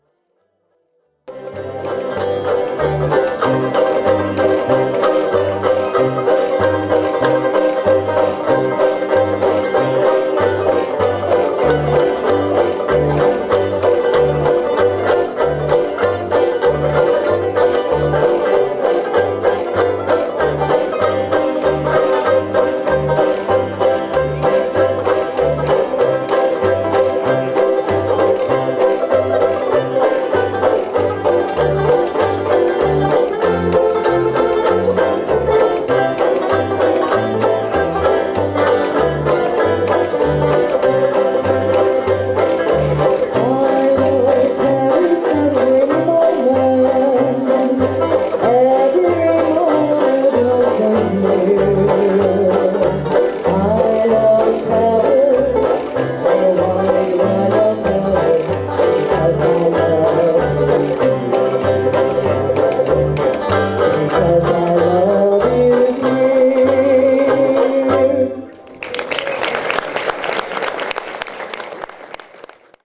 8-beat intro.
Part 1 is in the key of Cm, Part 2 is in C.